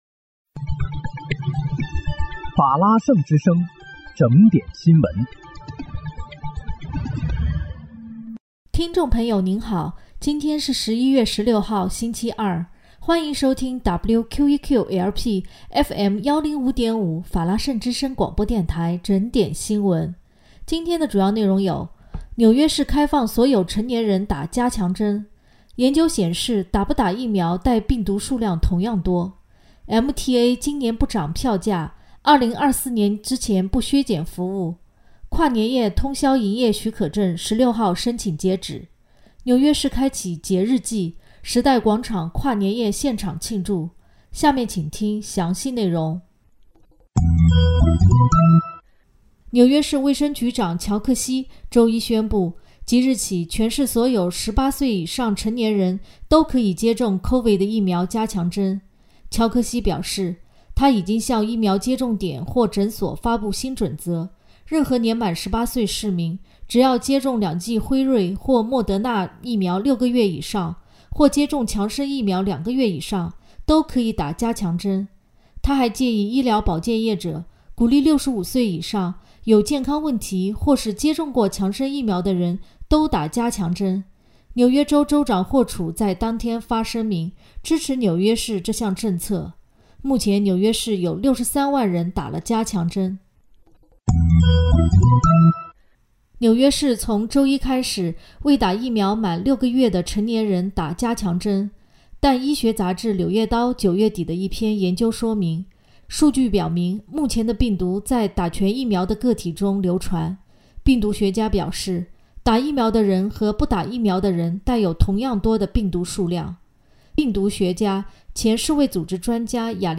11月16日（星期二）纽约整点新闻